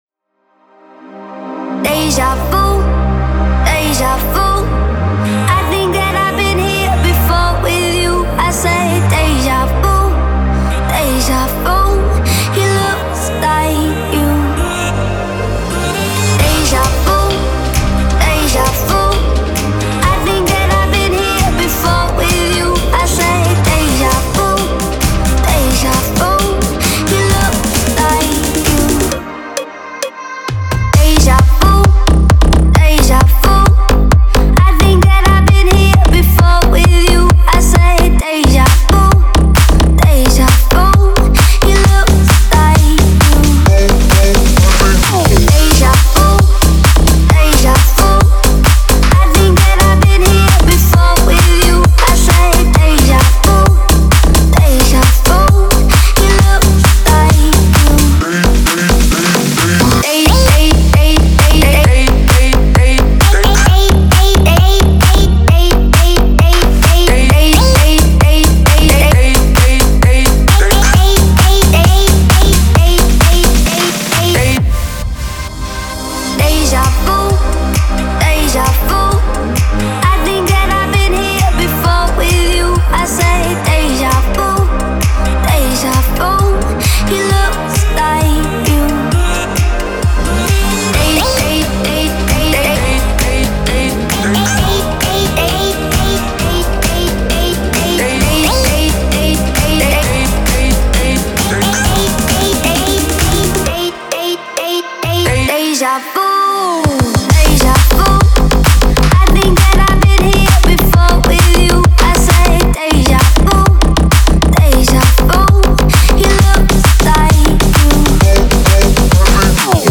который сочетает в себе элементы электронной и поп-музыки.